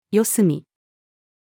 四隅-female.mp3